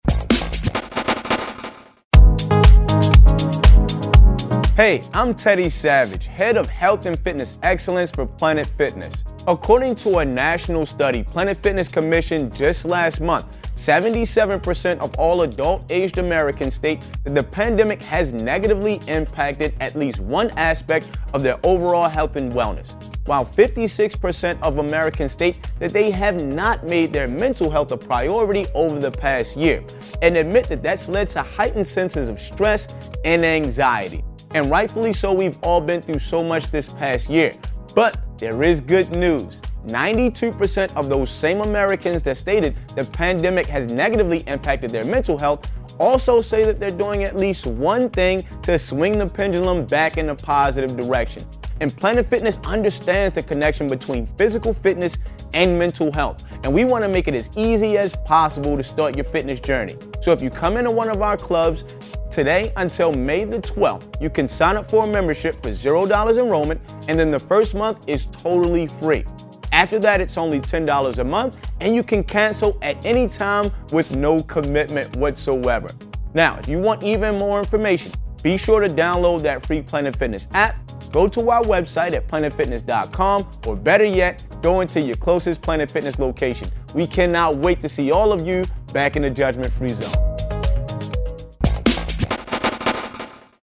in the LifeMinute Studios to share how we can improve our mental and physical wellness to lead a healthier and more balanced life.